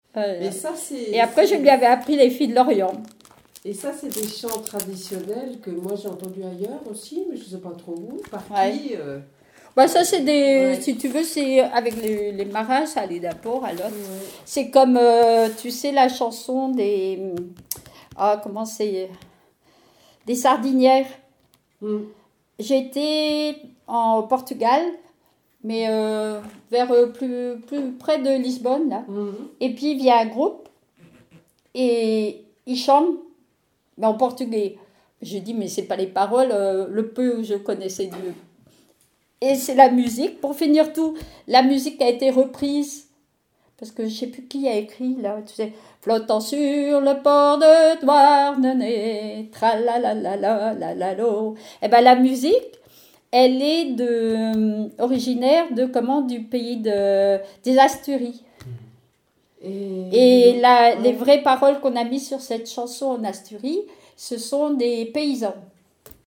témoignages et bribes de chansons
Catégorie Témoignage